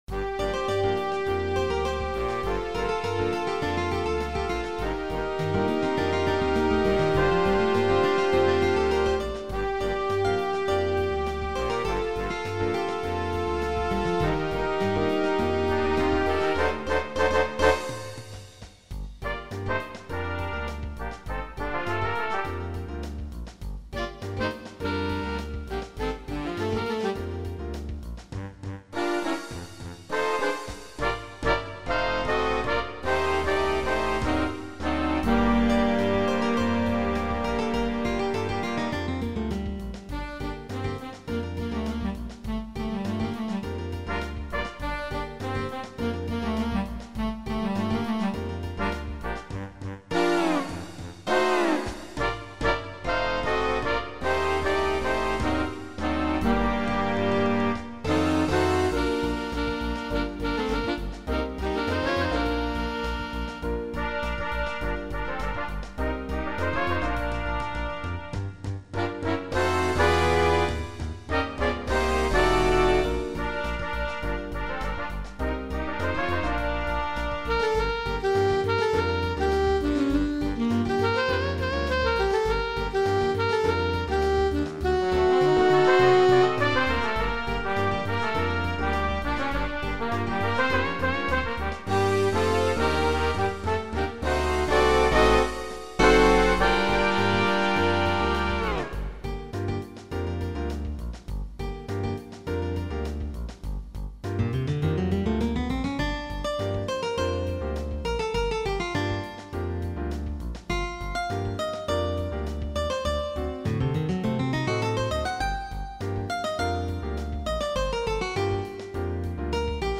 Voicing: Combo